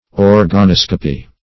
Organoscopy \Or`ga*nos"co*py\, n.